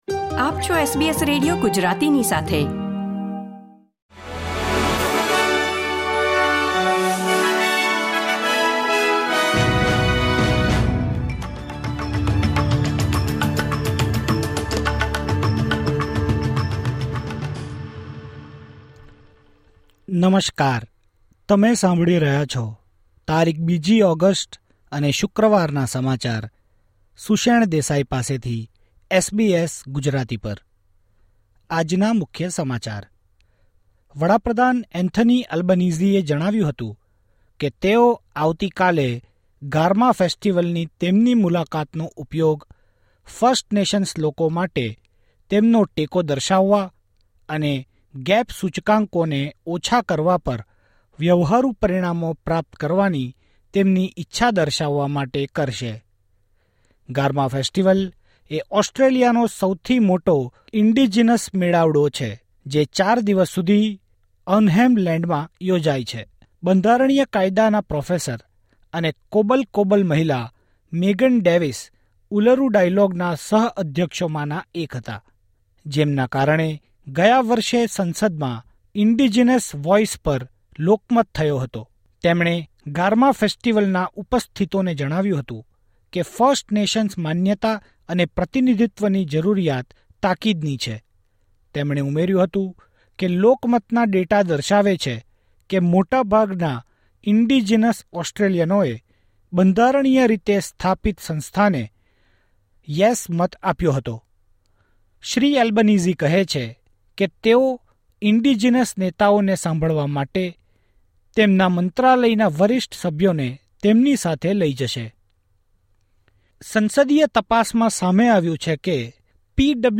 SBS Gujarati News Bulletin 2 August 2024